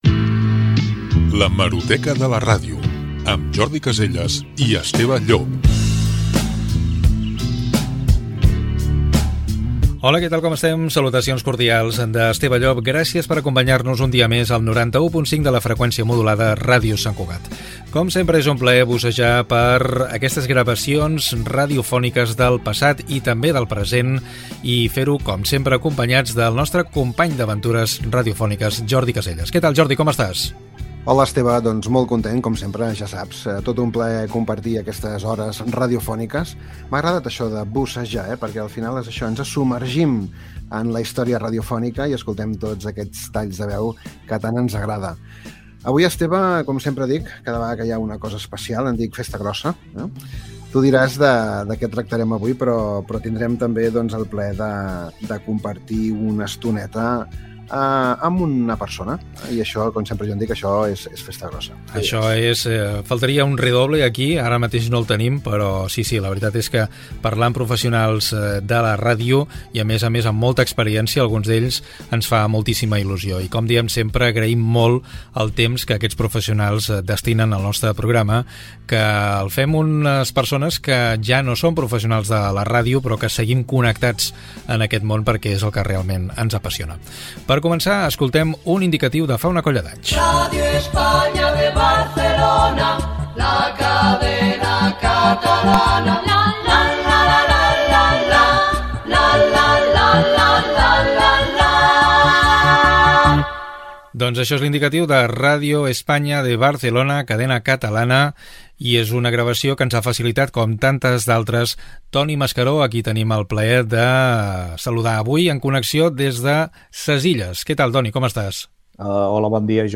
Presentació del programa. Indicatiu de Radio España de Barcelona Cadena Catalana.
Divulgació